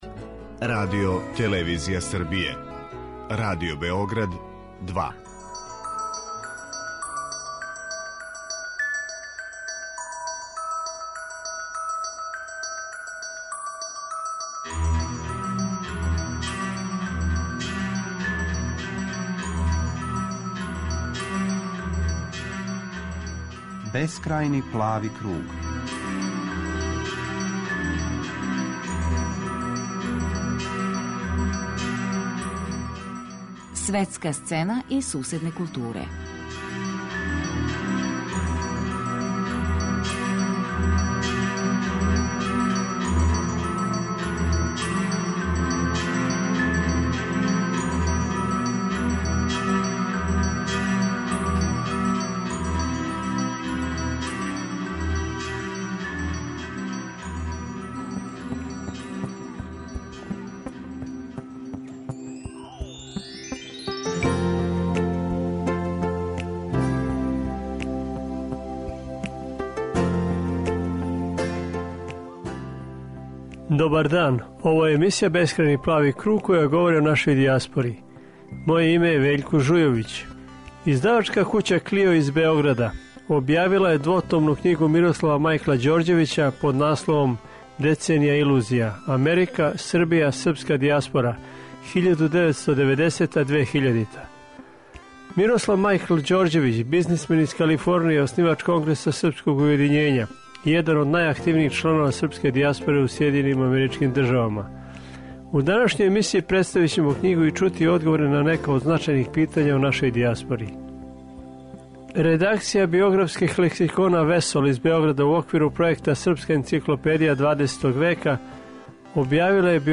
Емисија о српској дијаспори